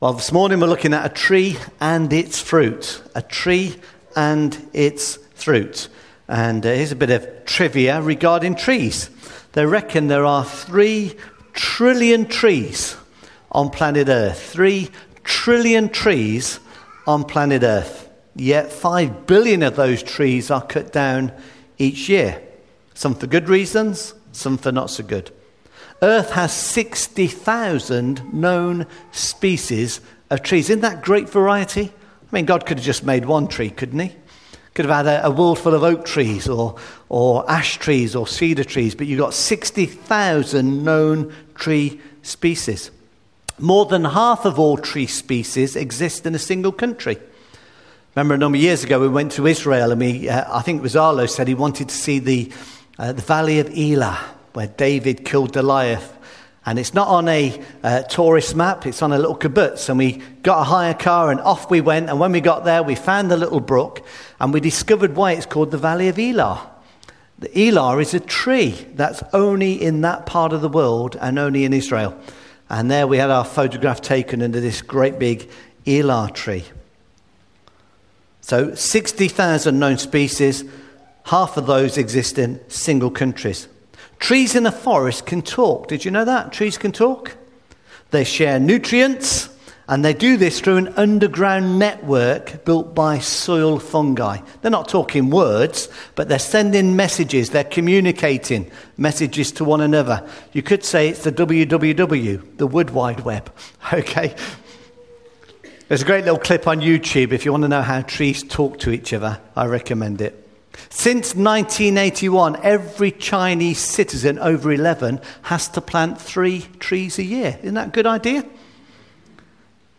Luke chapter 6 verses 43-45 – sermon